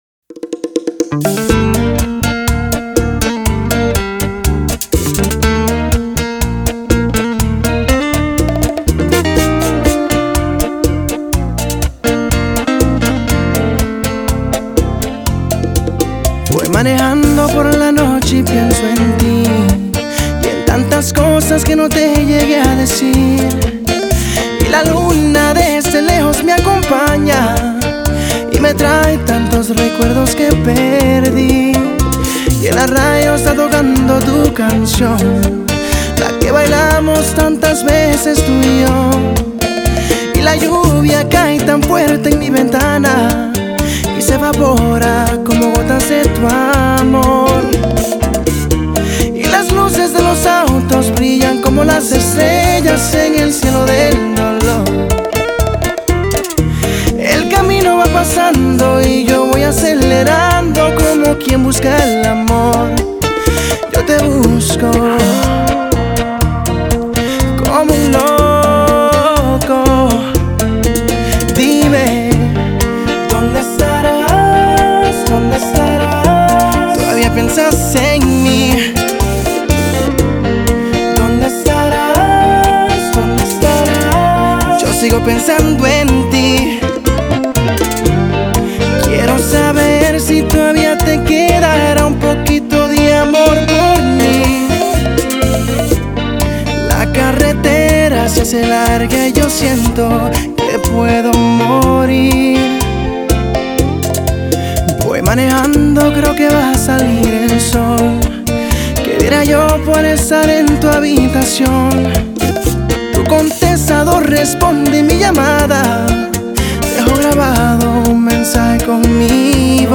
una romántica, poética y melodiosa bachata moderna